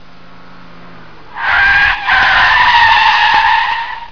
CARCAR SCREECHING
Ambient sound effects
carcar_screeching.mp3